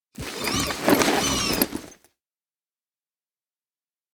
dc0f4c9042 Divergent / mods / Ledge Climbing Mantling / gamedata / sounds / ledge_grabbing / Vaulting / Exo / exo3.ogg 45 KiB (Stored with Git LFS) Raw History